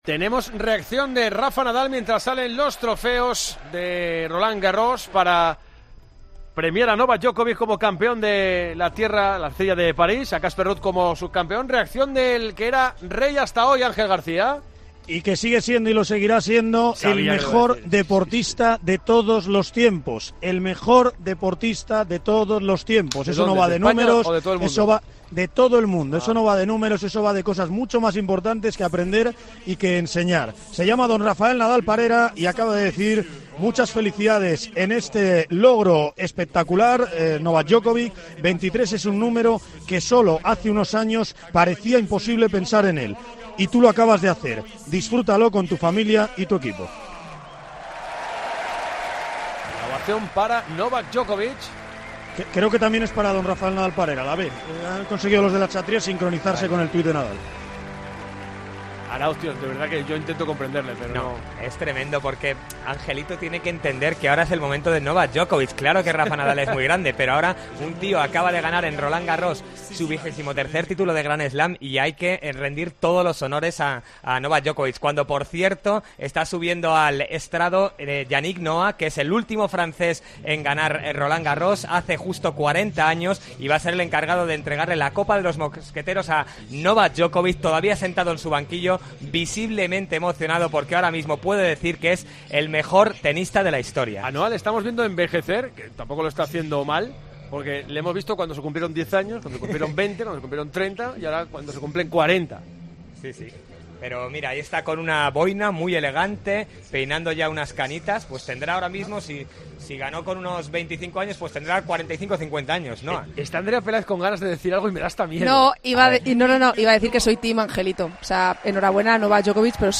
DEBATE EN TIEMPO DE JUEGO